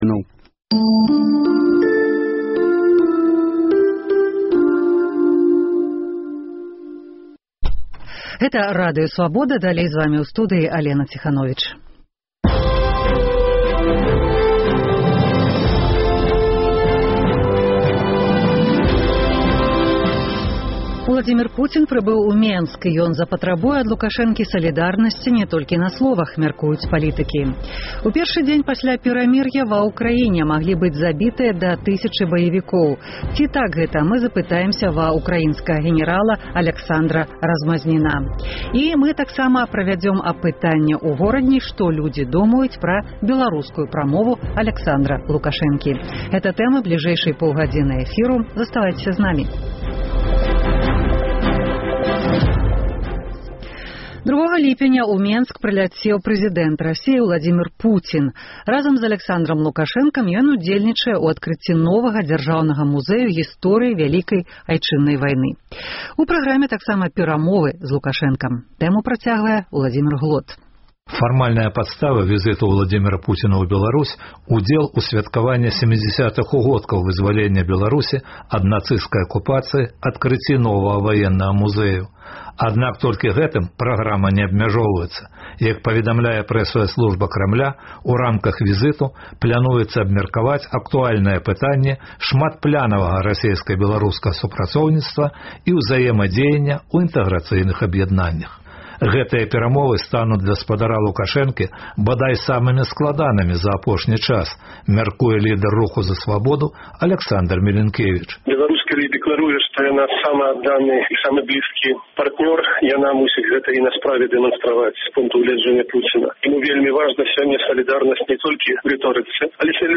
У інтэрвію Свабодзе прафэсар адкажа на пытаньні аб прычынах звальненьня, студэнтаў, дзяцей і рыбак.